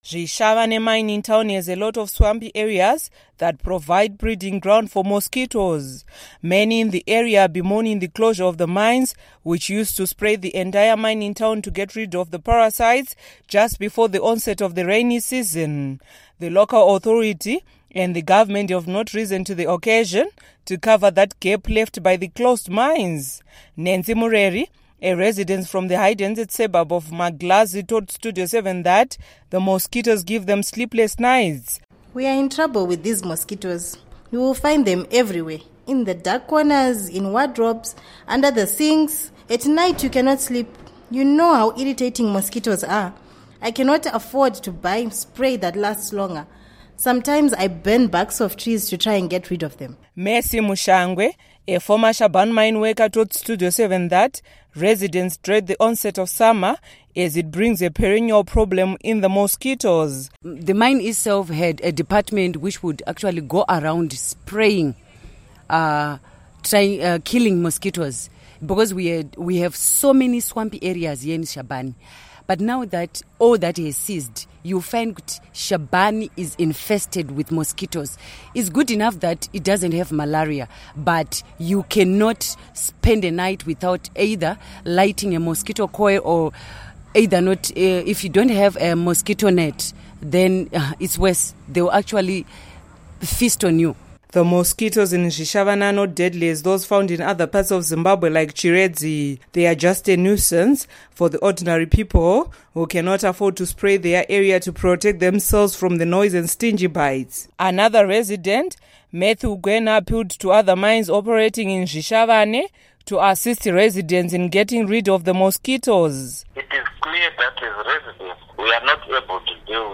Report on Mosquitoes